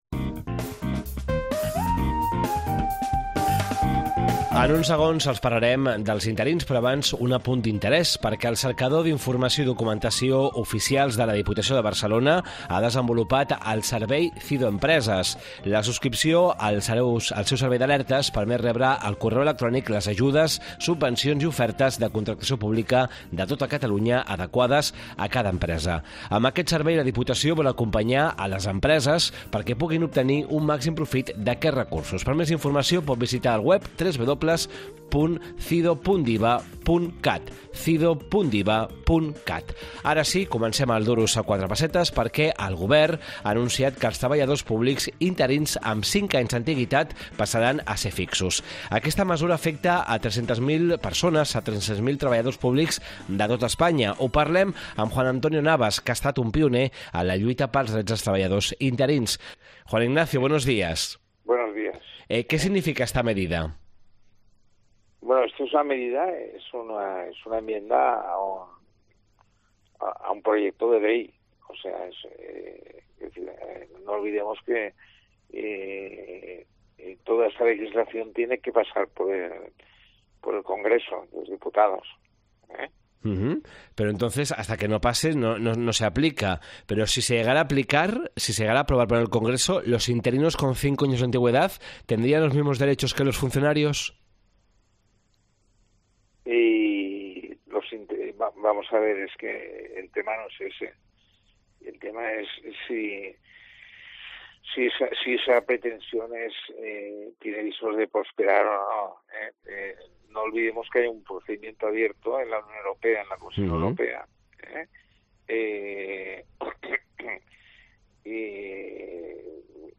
Duros a quatre pessetes, el programa d’economia de COPE Catalunya i Andorra.